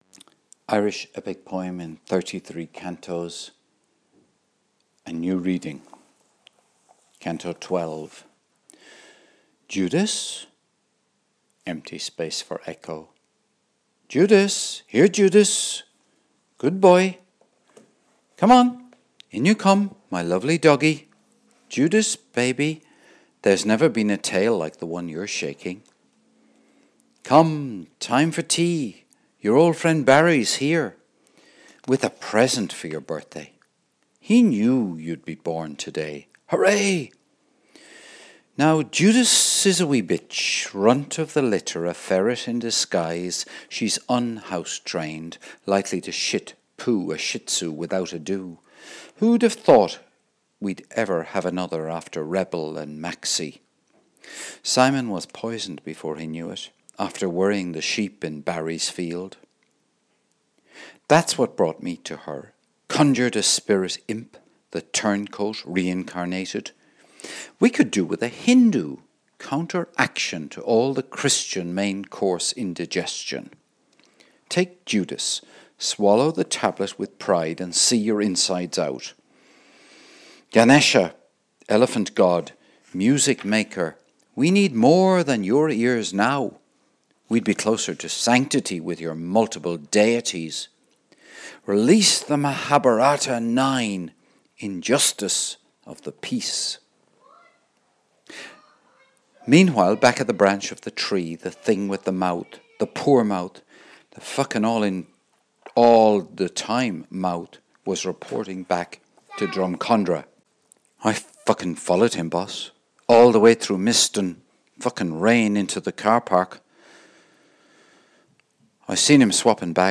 Irish Epic Poem in 33 Cantos (12-14/1) - a new reading